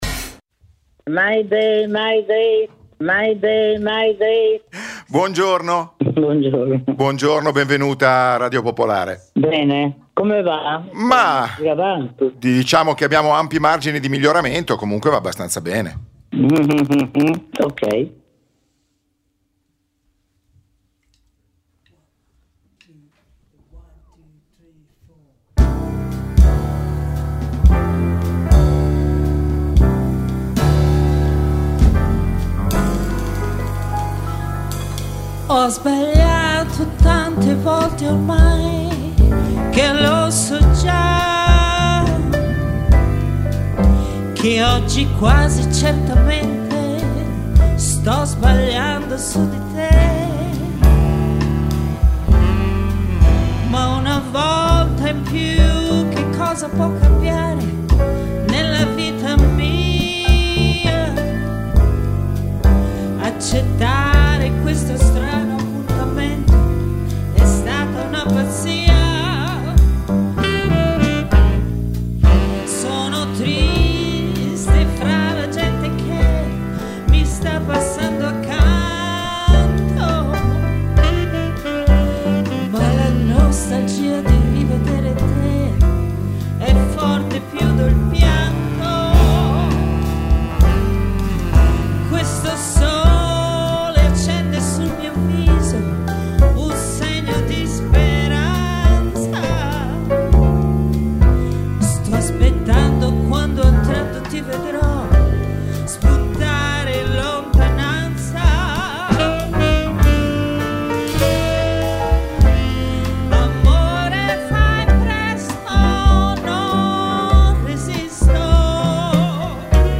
Ogni giovedì alle 21.30, l’auditorium Demetrio Stratos di Radio Popolare ospita concerti, presentazioni di libri, reading e serate speciali aperte al pubblico.